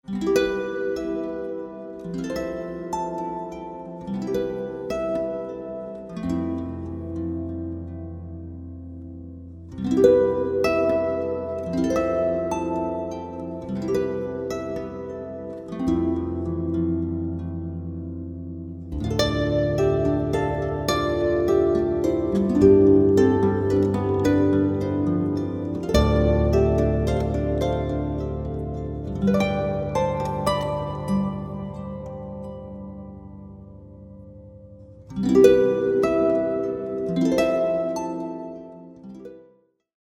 Harp only version